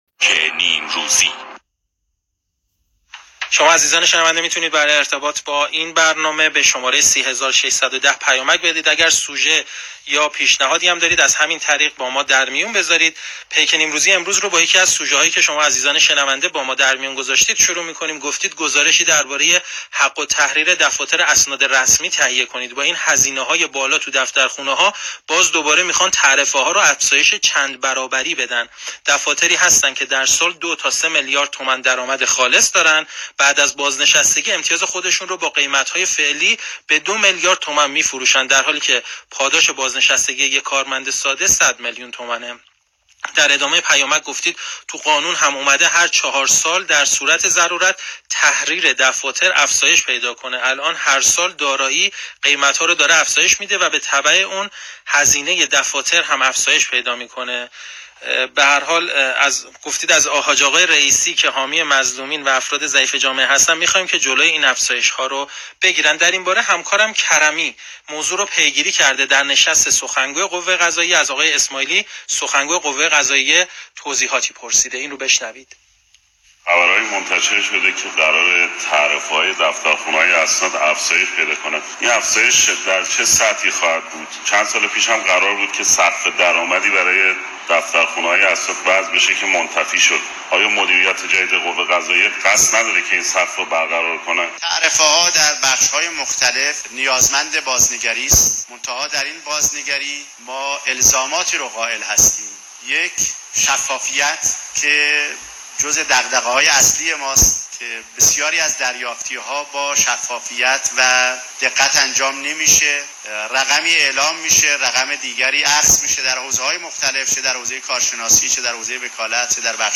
بخش خبری پیک نیمروزی رادیو ایران و طرح موضوع درآمدهاي نجومي
به گزارش بولتن نیوز، پیک نیمروزی در بخش خبري ساعت ١٤ راديو ايران، ٩٨/٥/٢٢ به طرح موضوعاتي مانند درآمدهاي نجومي و فروش امتياز و افزایش تعرفه ‌هاي دفاتر اسناد رسمي پرداخت.